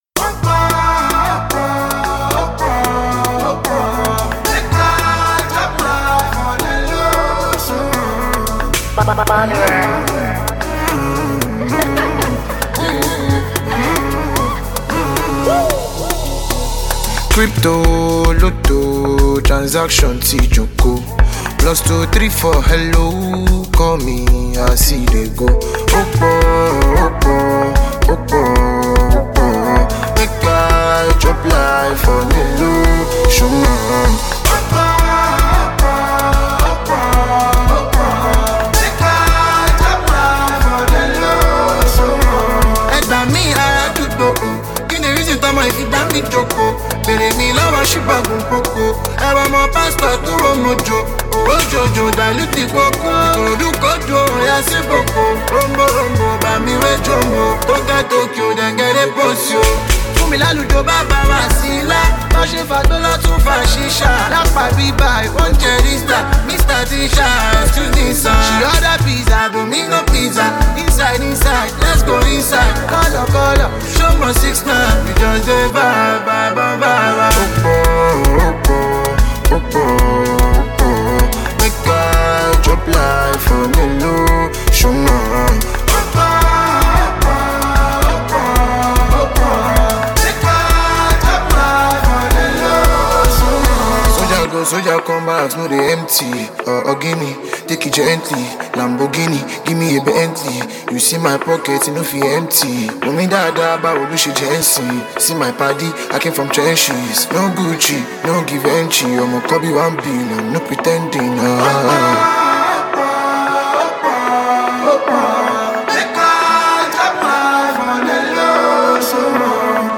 coll afrobeat tune to jam with